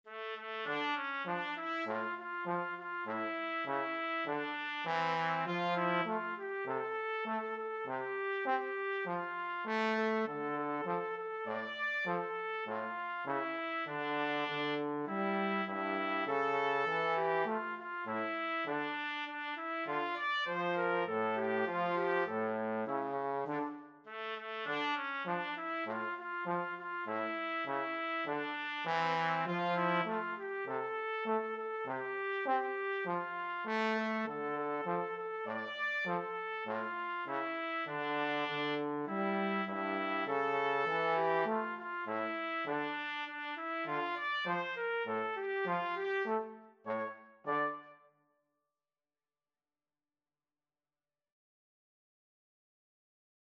4/4 (View more 4/4 Music)
Moderato
world (View more world Trumpet-Trombone Duet Music)